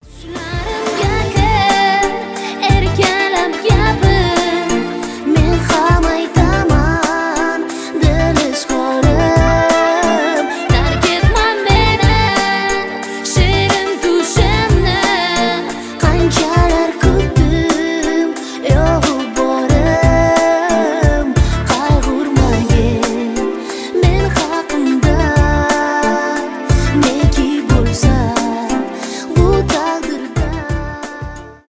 фолк , поп